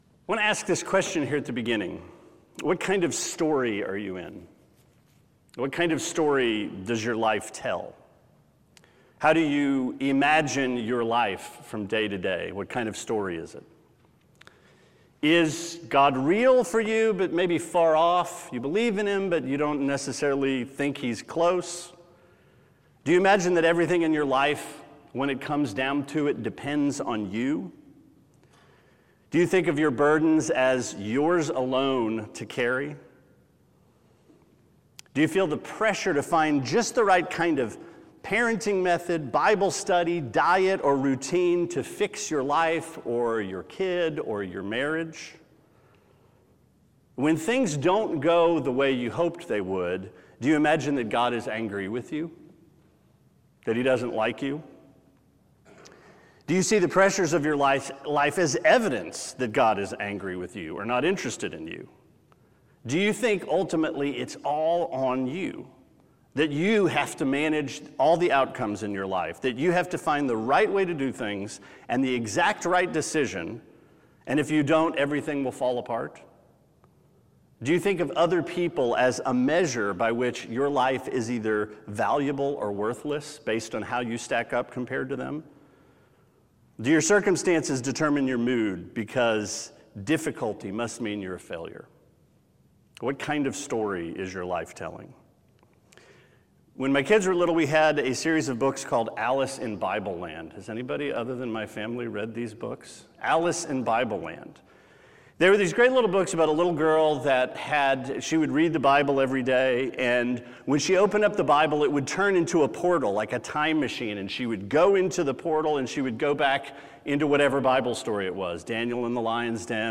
Sermon 12/12: Acts 13-14: TCF in Bibleland – Trinity Christian Fellowship